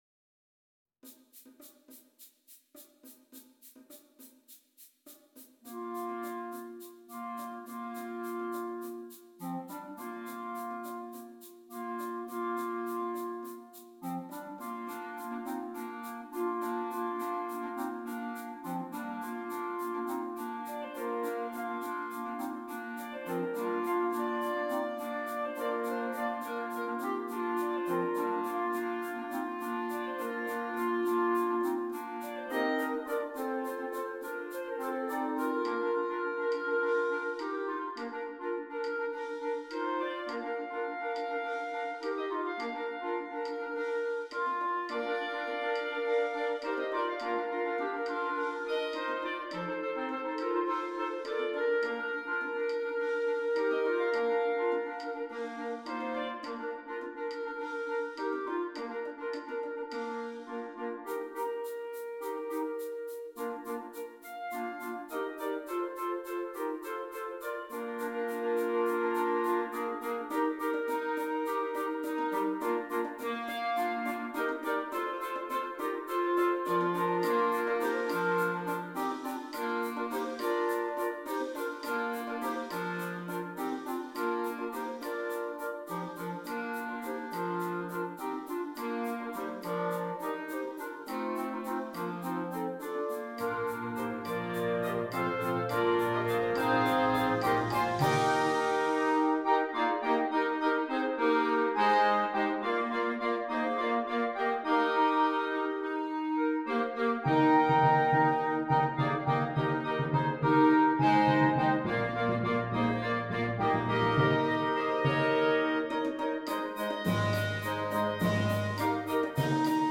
8 Clarinets and Percussion
Traditional